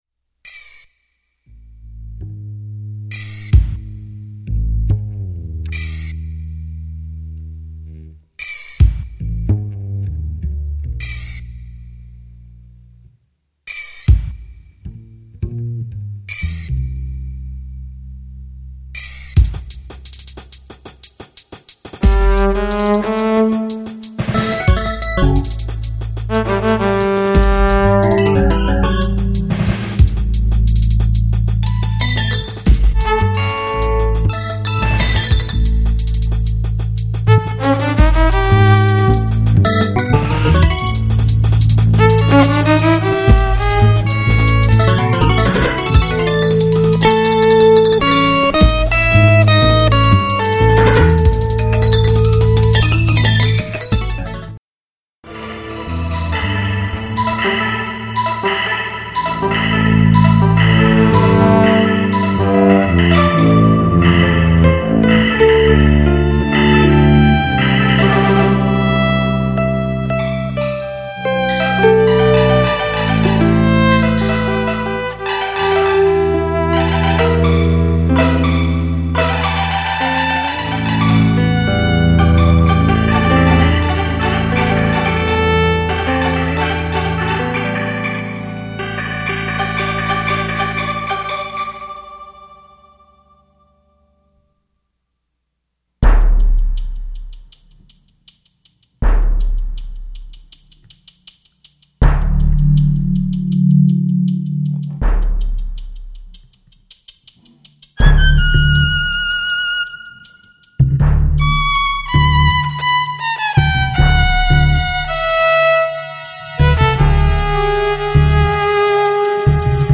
All music generated and recorded live in concert.